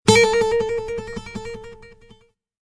Descarga de Sonidos mp3 Gratis: guitarra 17.